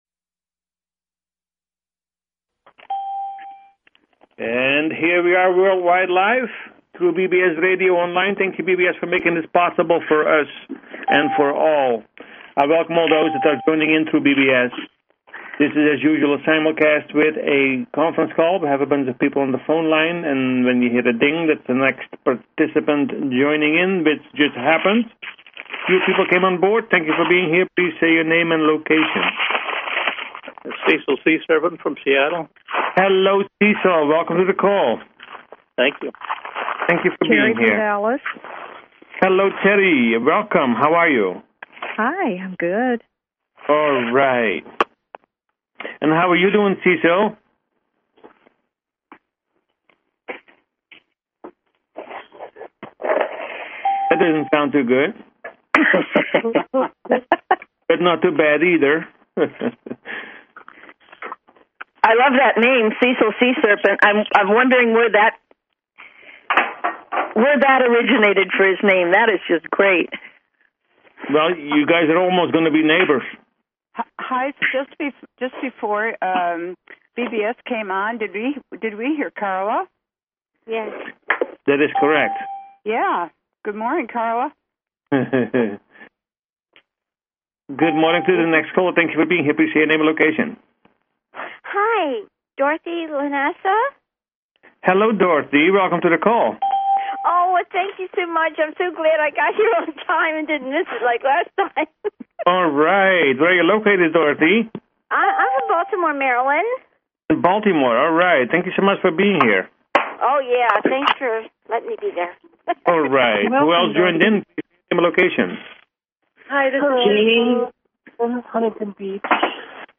Talk Show Episode, Audio Podcast, Personal Planetary Healing Meditation